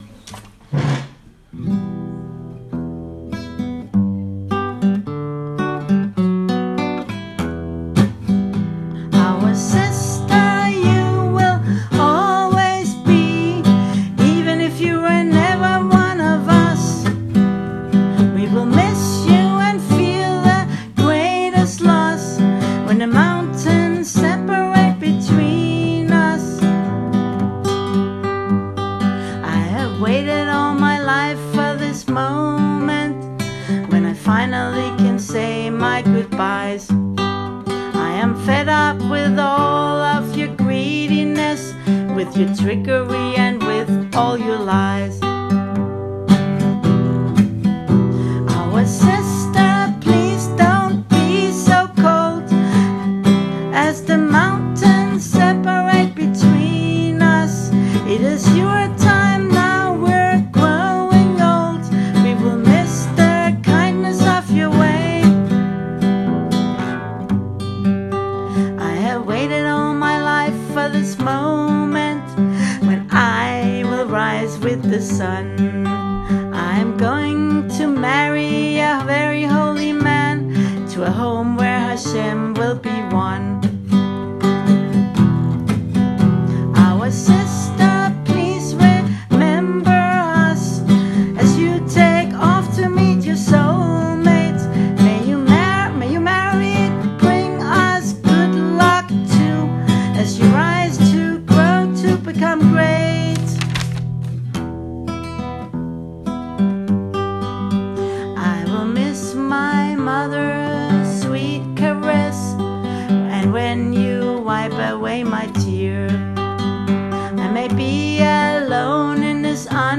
The poem below, written as a dialogue between Rivkah and her family, is an attempt to answer this question. I also composed a melody for the poem which you can listen to